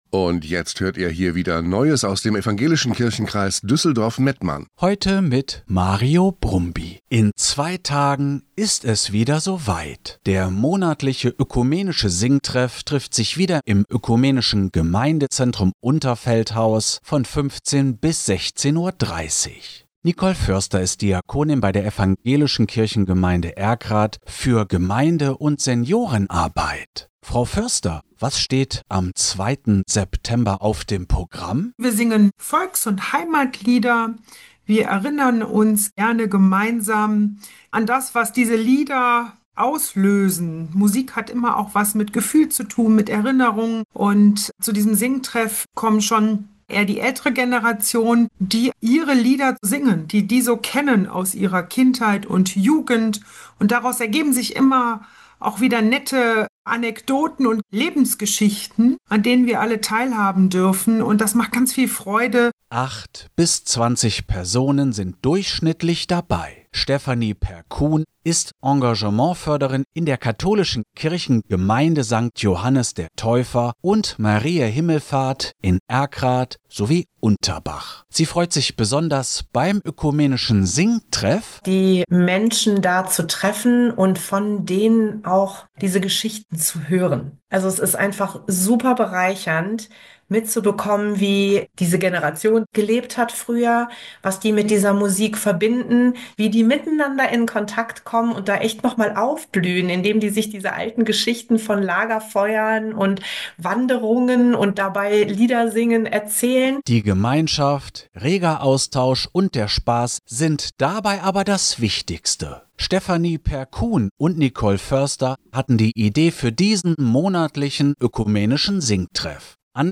Radiobeitrag über den ökum. Singtreff